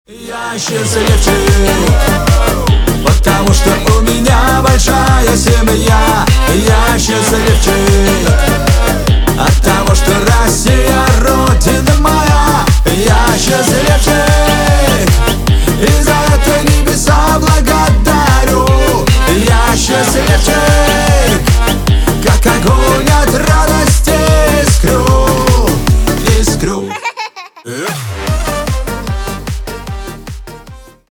на русском веселые